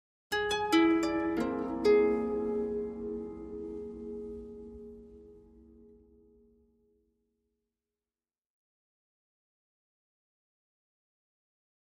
Harp, Arpeggio Reminder, Type 4